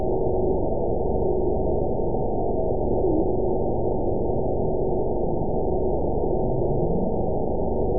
event 922285 date 12/29/24 time 03:25:44 GMT (11 months, 1 week ago) score 9.37 location TSS-AB02 detected by nrw target species NRW annotations +NRW Spectrogram: Frequency (kHz) vs. Time (s) audio not available .wav